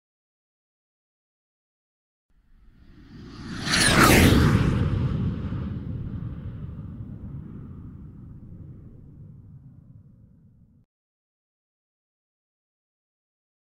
جلوه های صوتی
دانلود صدای رد شدن جت 2 از ساعد نیوز با لینک مستقیم و کیفیت بالا